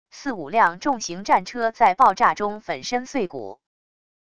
四五辆重型战车在爆炸中粉身碎骨wav音频